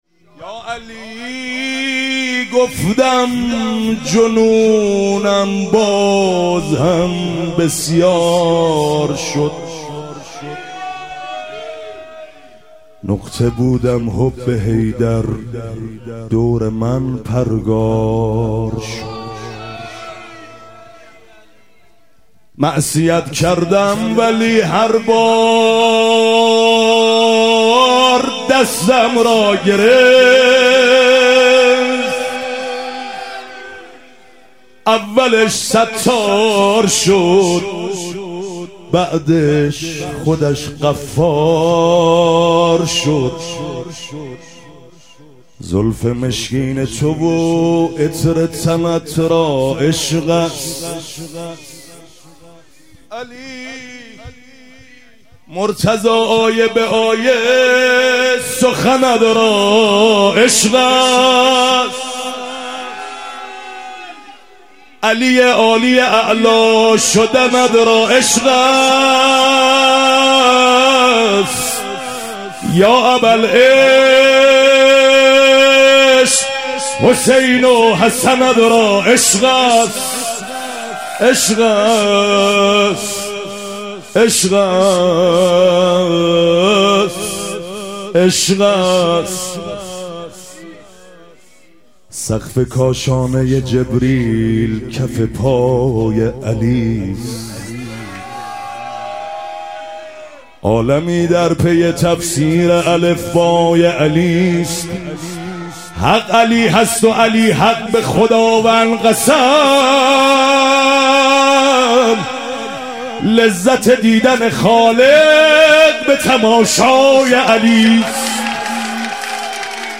ظهور وجود مقدس امام جواد و حضرت علی اصغر علیهم السلام - مدح و رجز